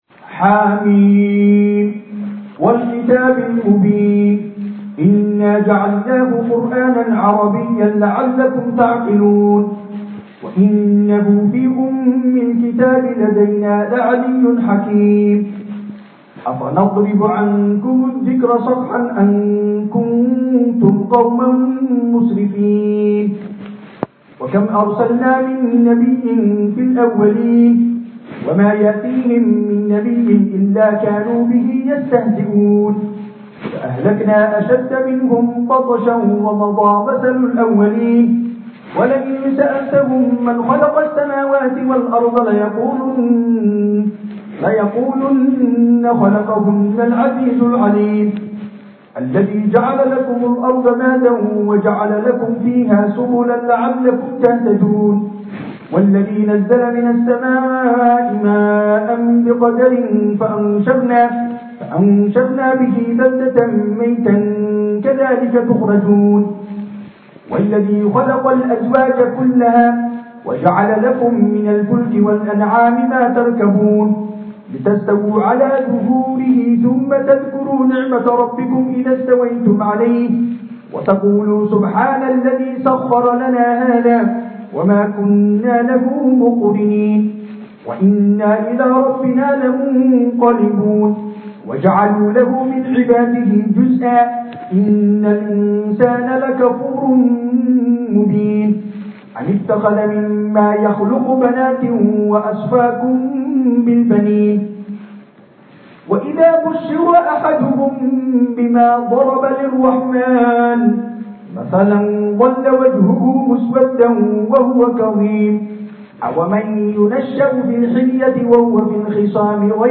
Salaada Taraaxwiixda iyo Masjidka Somalida ee Al-Taqwaa